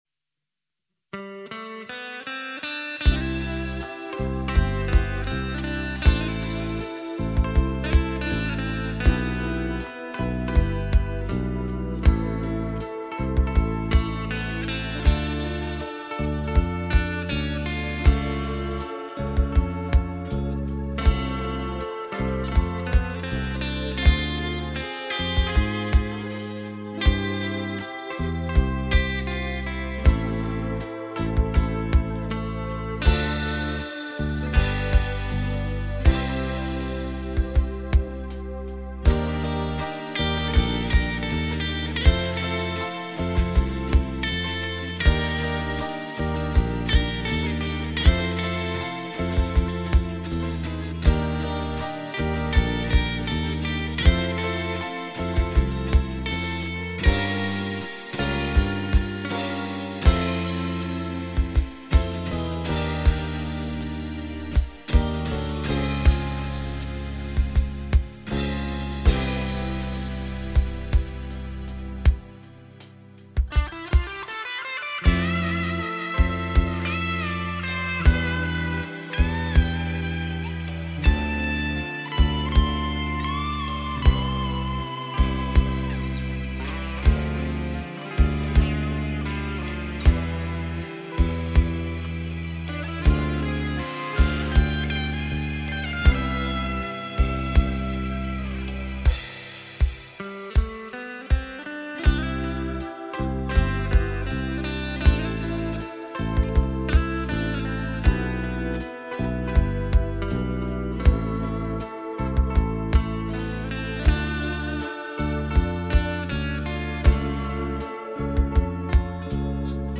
もっと派手な曲にしようかと思ったのですが、コンプレッサーのクリーントーンがマッチするものを選んでみました。
GarageBandを使い、ギターはPOD X3でデジタル化しました。ギターは全てEMGピックアップのMOONのストラトです。
●メロディ：フロントPU・1番は自作OrangeSqueezer、2番はKeeleyの4ノブと変更してみました。
●中間のギターソロ：リアPU・自作OrangeSqueezer+Providence SonicDrive
●カッティング：フロント+センターPU・自作OrangeSqueezer
●その他：全てGarageBand付属の音源
まあ、結論を言ってしまえば、OrangeSqueezerは味がある音色で、ROSS系のKeeleyは存在を感じさせない自然な感じ、と、同じコンプレッサーでもかなり違うのですが、EMGとOrangeSqueezerの組み合わせは実はあまりOrangeSqueezerっぽい音ではない気もします。